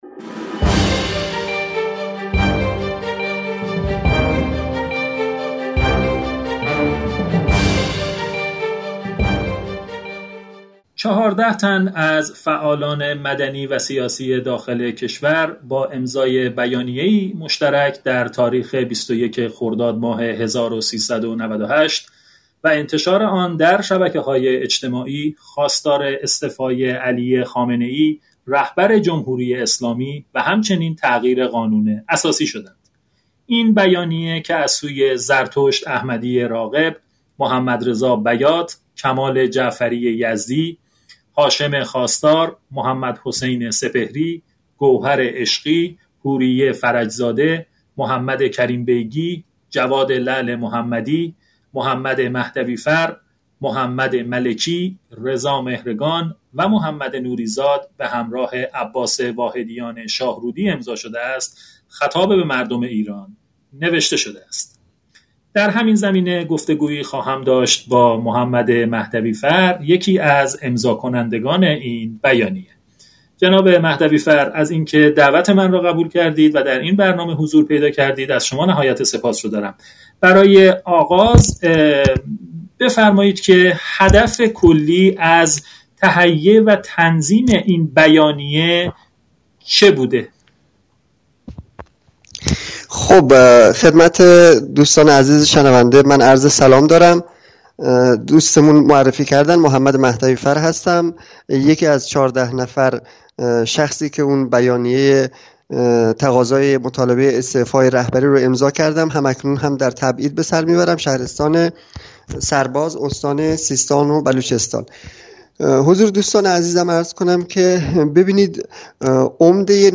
گفتگوی حقوق بشر در ایران